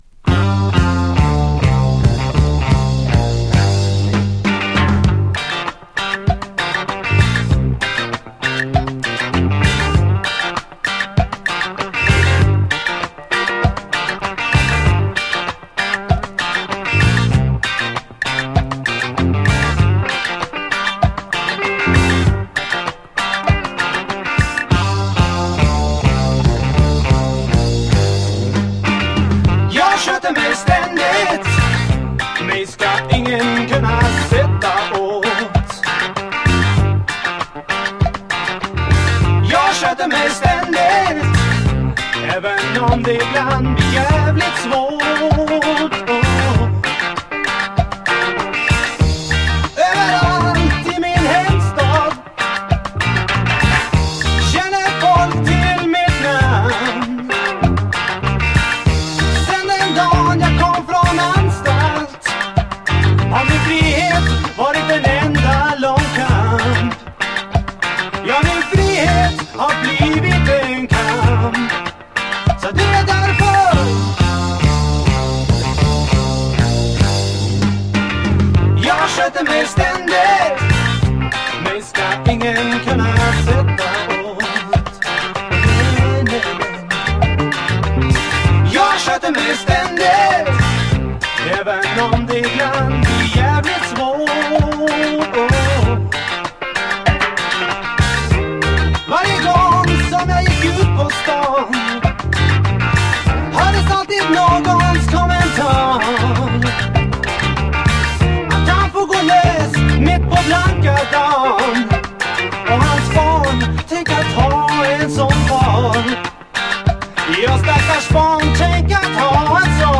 スウェーデンの5人組プログレ・バンド